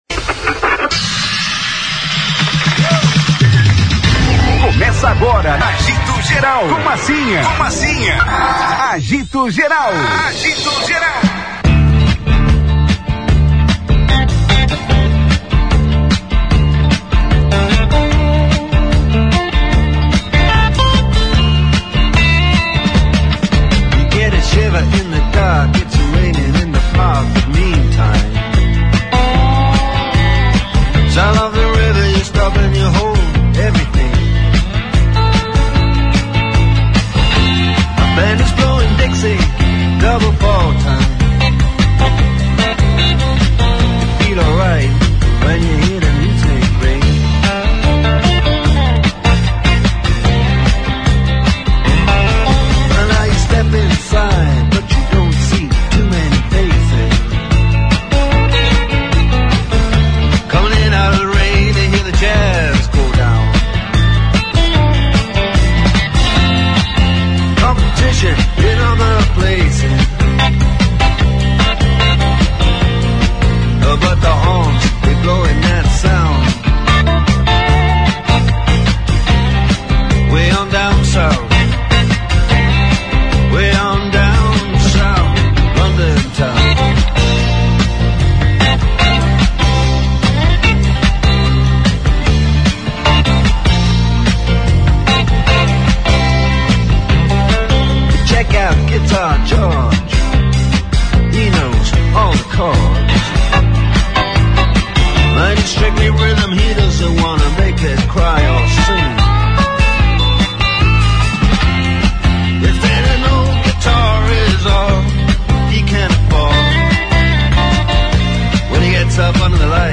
Durante entrevista ao programa Agito Geral nesta sexta-feira (6) , o candidato destacou a importância de modernizar a instituição para atender às exigências globais.